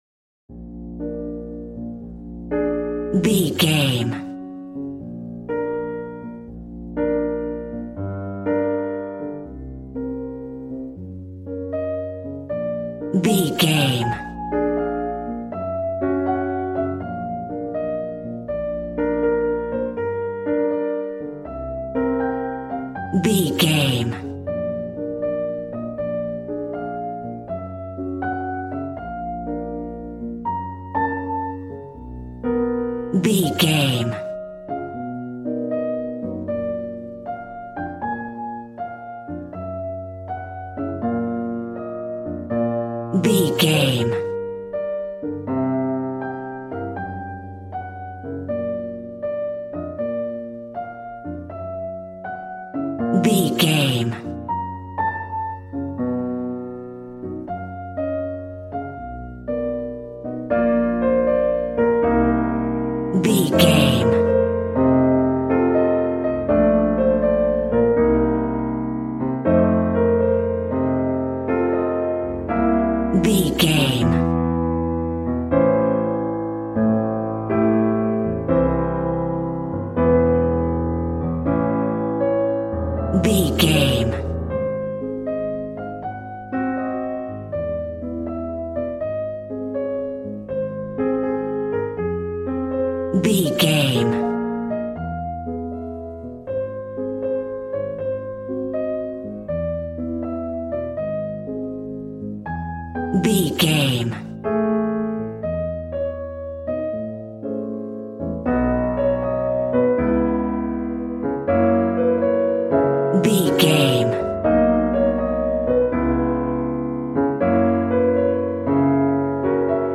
Aeolian/Minor
B♭
sexy
smooth
piano
drums